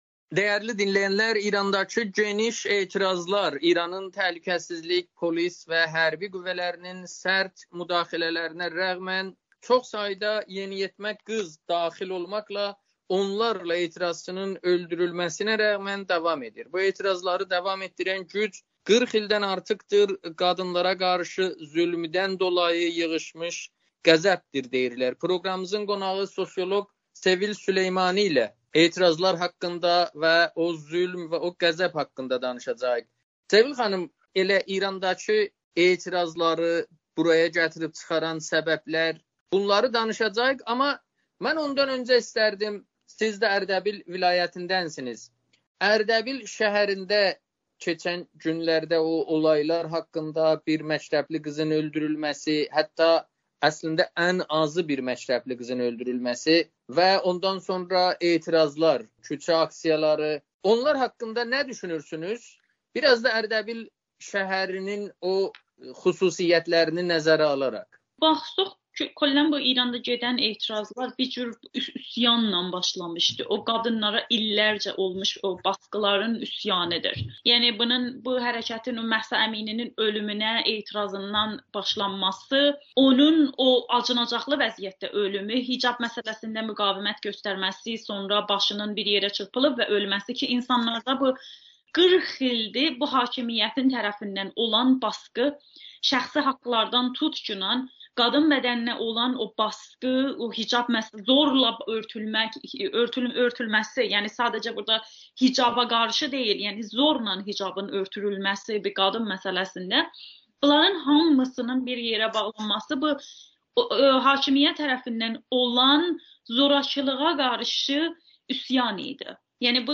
Amerikanın Səsi ilə söhbətdə həftələrdir İranda davam etməkdə olan geniş etirazlar haqqında danışıb. O, Ərdəbil kimi şəhərlərdə məktəbli uşaqların bu etirazlara qatılmasının səbəblərini şərh edib.